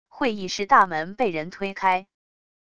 会议室大门被人推开wav音频